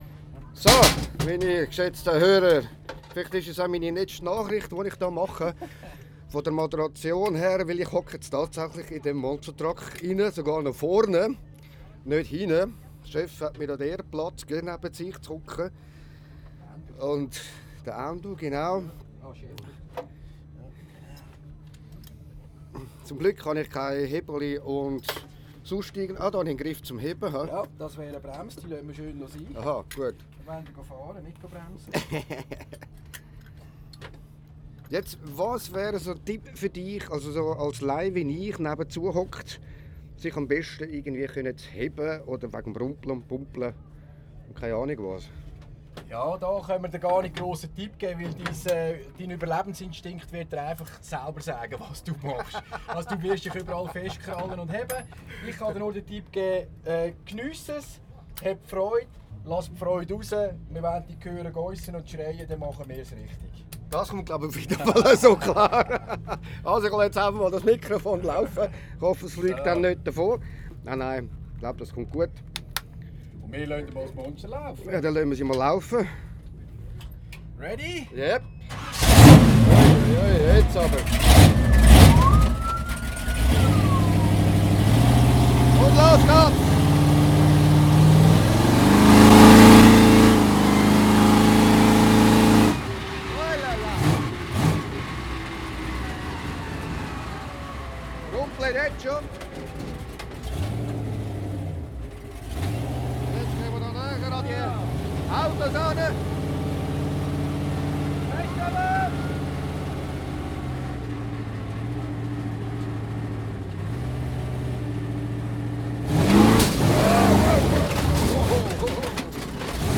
Radio15 Interview am Int. Trucker Treffen